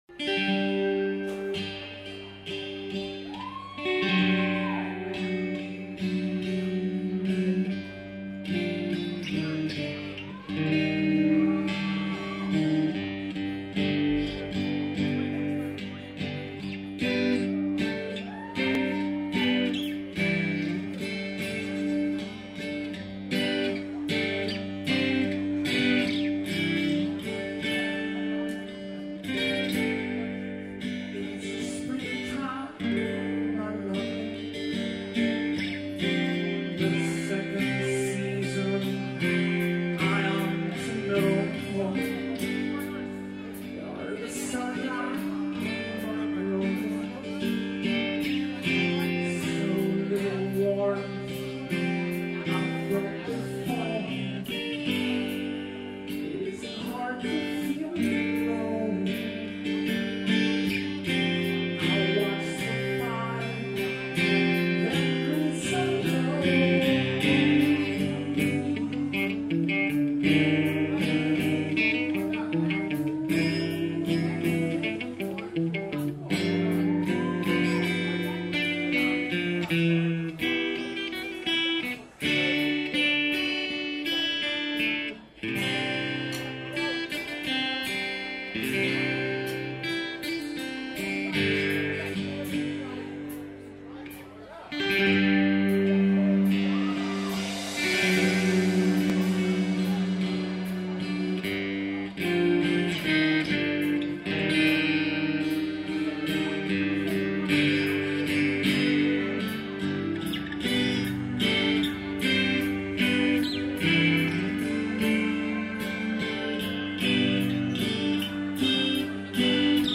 All media was recorded live and is unedited.